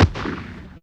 Shotgun.wav